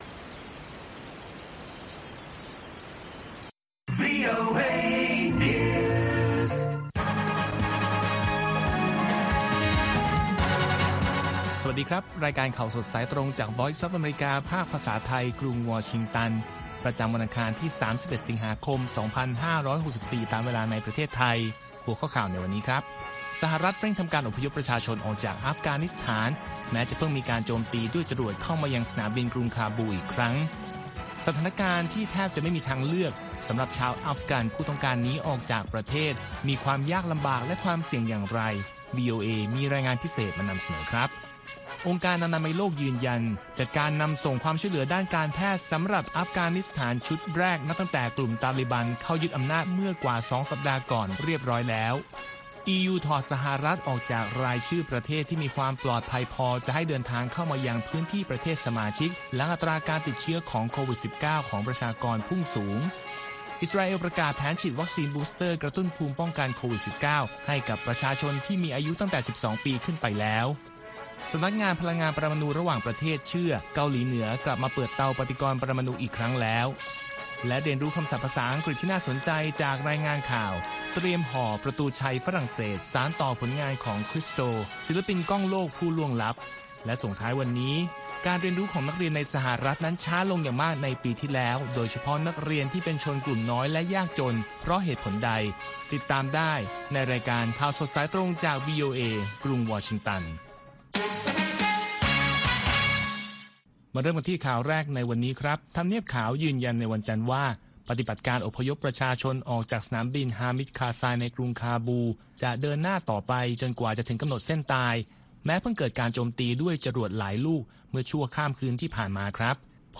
ข่าวสดสายตรงจากวีโอเอ ภาคภาษาไทย ประจำวันอังคารที่ 31 สิงหาคม 2564 ตามเวลาประเทศไทย